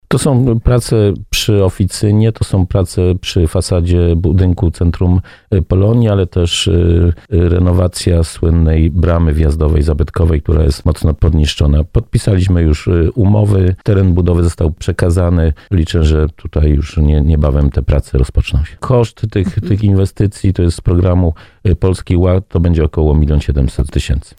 Mówił o tym w audycji Słowo za Słowo starosta dąbrowski Lesław Wieczorek.